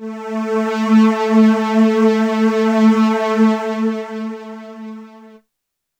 strTTE65015string-A.wav